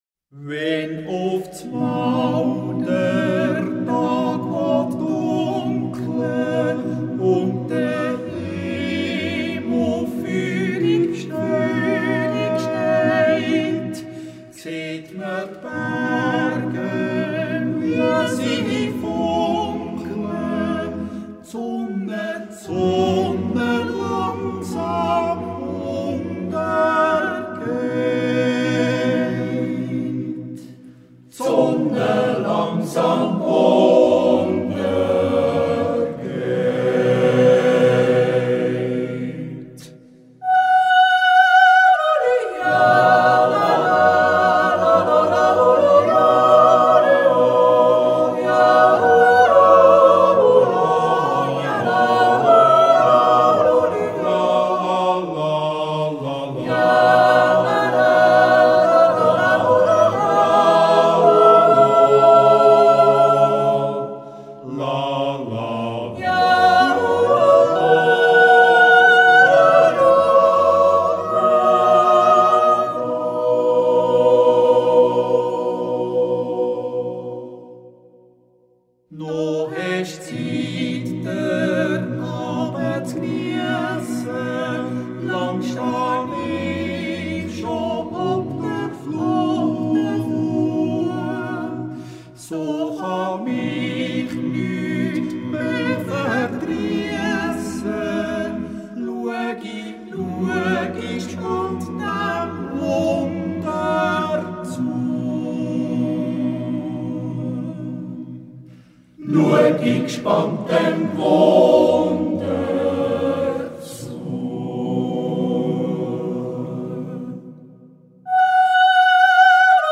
Yodel songs and Swiss folk dances.
Yodel song.